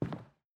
Footsteps / Carpet
Carpet-07.wav